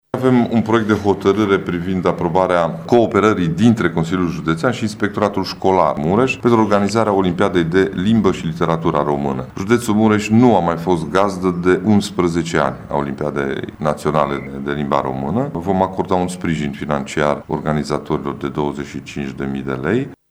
Președintele CJ Mureș, Ciprian Dobre: